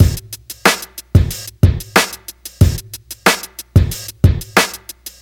92 Bpm 00s Rap Drum Loop Sample F Key.wav
Free breakbeat sample - kick tuned to the F note.
92-bpm-00s-rap-drum-loop-sample-f-key-LFz.ogg